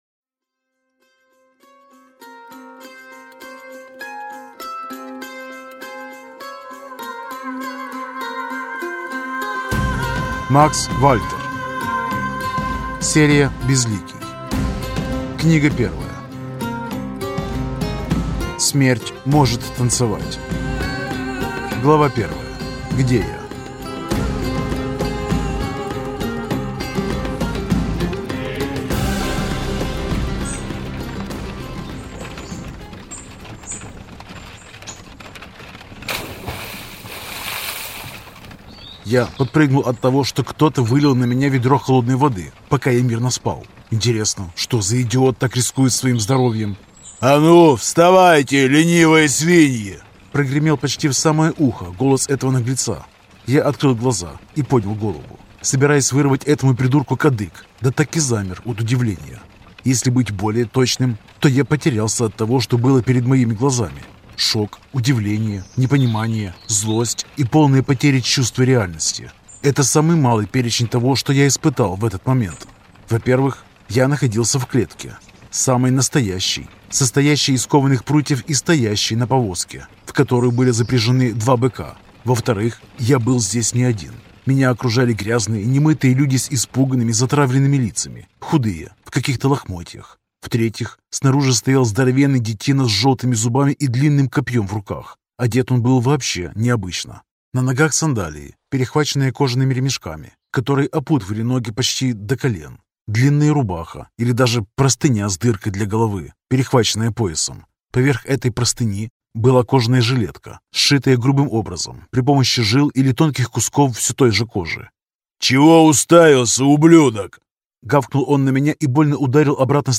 Аудиокнига Смерть может танцевать | Библиотека аудиокниг